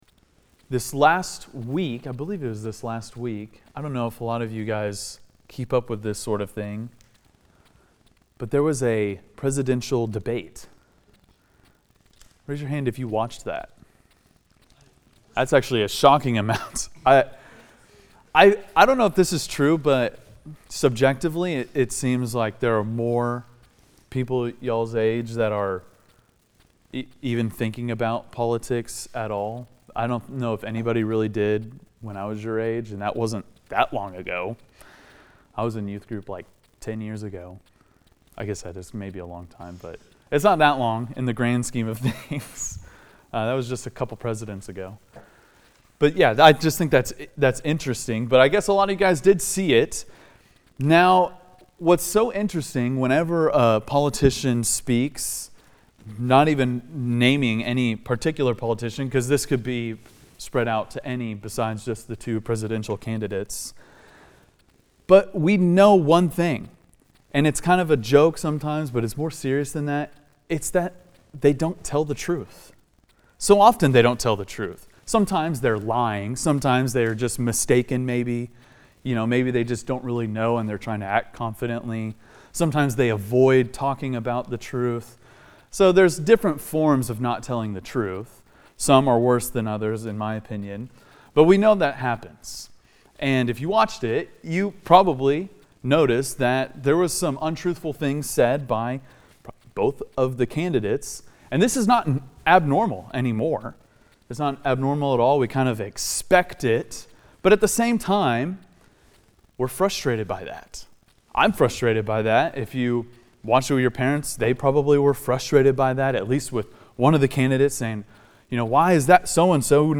teaches on the truthfulness